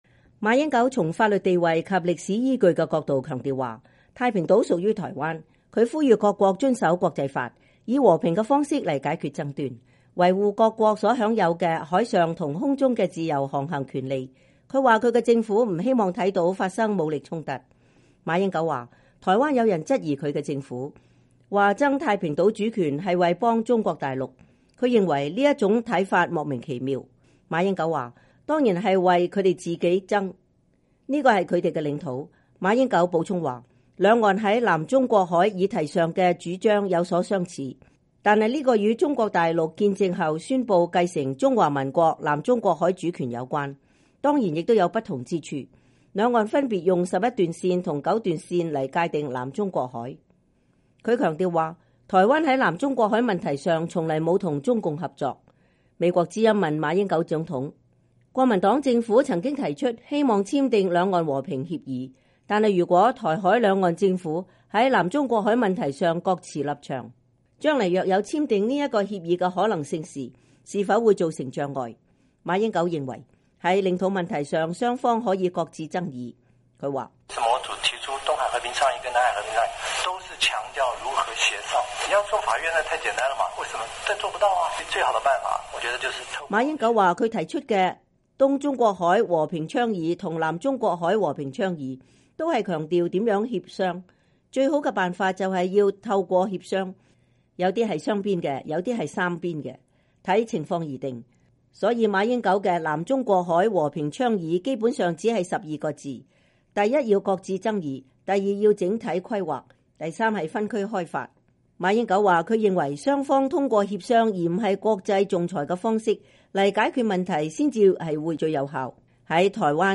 馬英九以問答方式強化政府官員對南中國海議題的了解，4月8日在台外交部以教師的方式向與會官員提出16個問題。
儘管話題嚴肅，但形式還比較輕鬆，講習會期間不斷發出笑聲和掌聲。